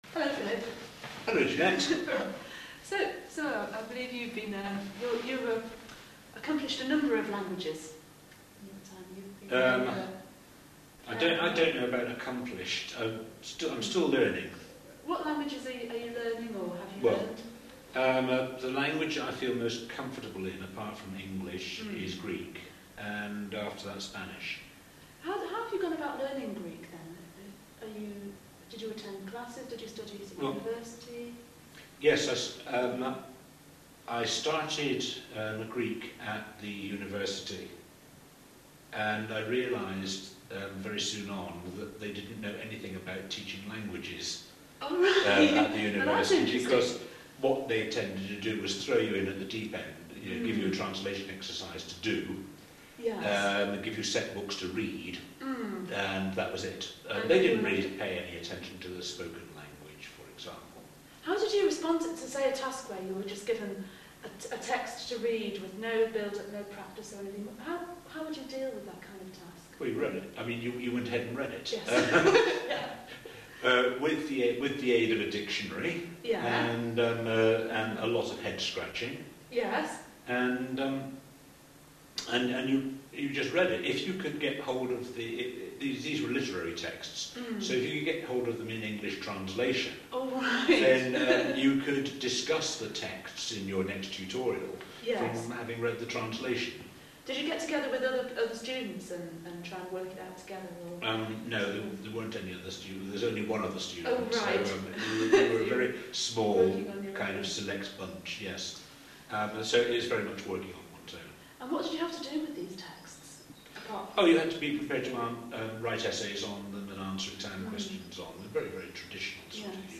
Listen to the learner's account of his learning experiences and answer the following: